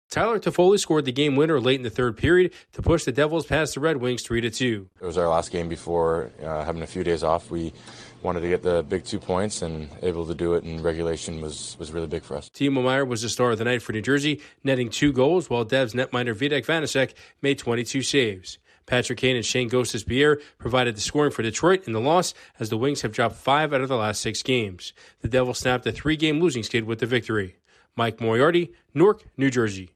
The Devils are one goal better than the Red Wings. Correspondent